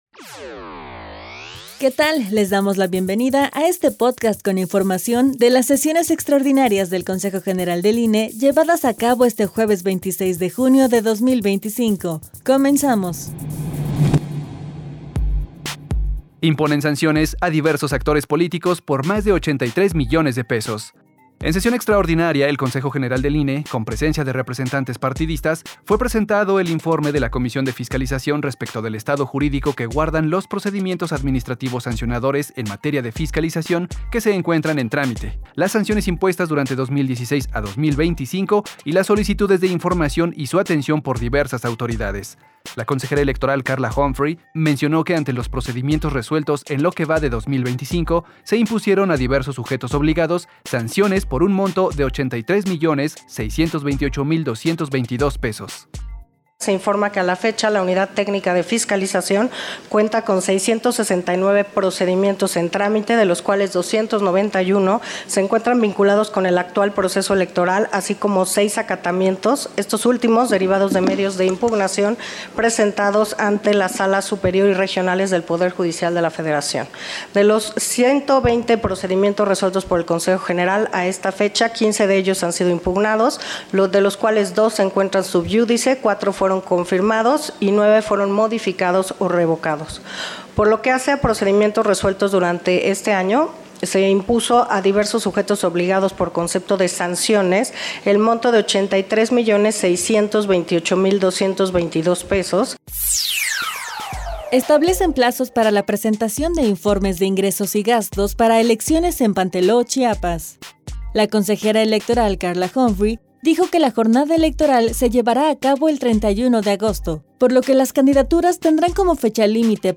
Audio de la sesión del Consejo General del INE, jueves 26 de junio de 2025